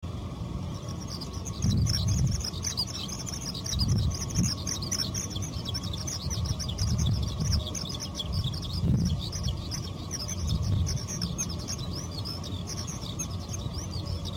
ヒ　バ　リ　属   ヒ　バ　リ　１　　1-01-01
鳴 き 声：ピュル、ピュルと鳴き繁殖期には飛びながら複雑な声で長時間囀るが、石や枯草に留まって囀ることもある。
鳴き声１
hibari01.mp3